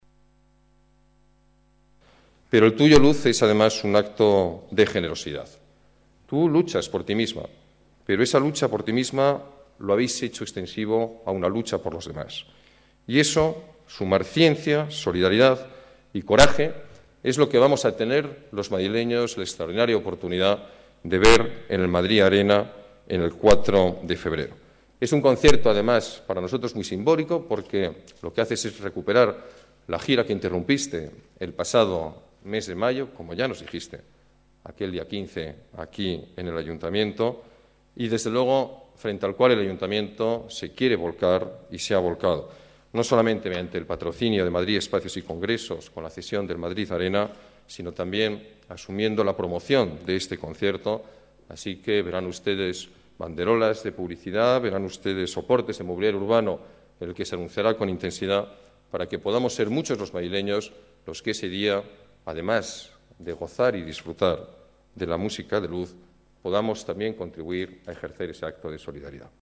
Nueva ventana:Declaraciones del alcalde, Alberto Ruiz-Gallardón.